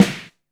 RATL WOOD.wav